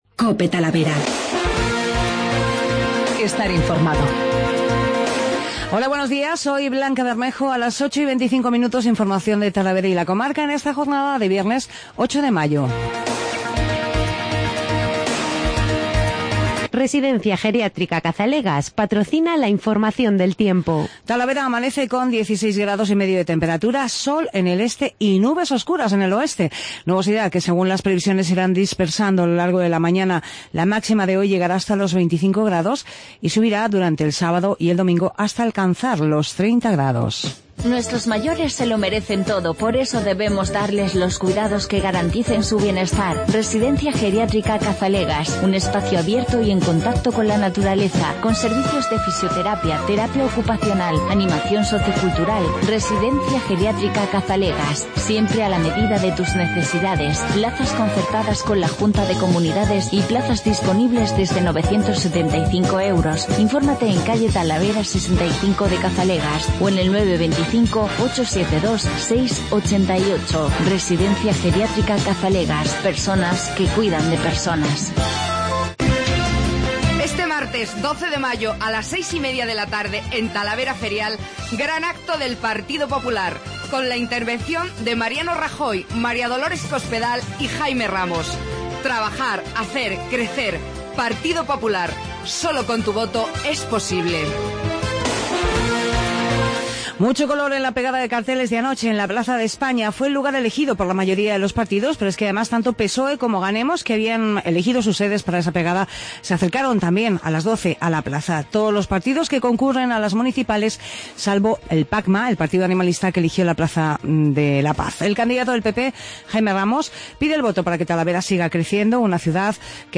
Pegada de carteles, comienzo de la campaña electoral. Los sonidos de los candidatos a la alcaldía.